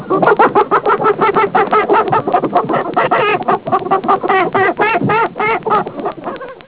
Hen 3
HEN_3.wav